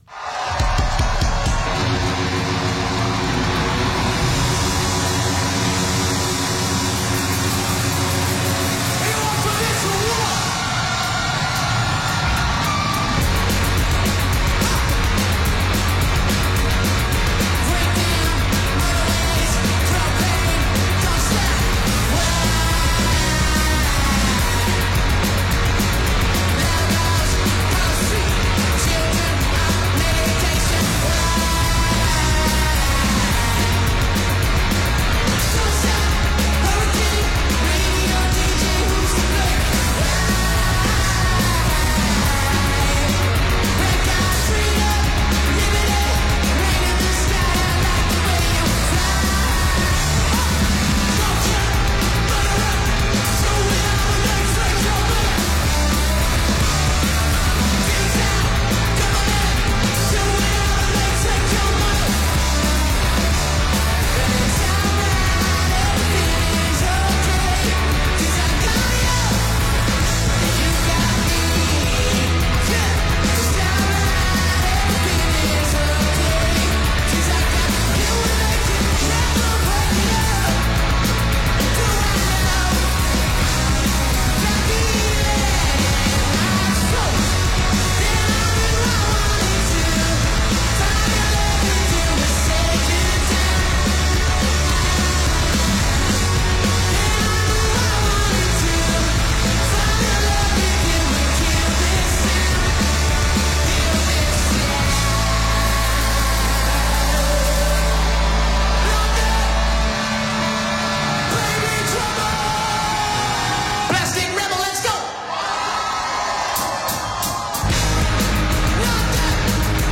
frenetic live show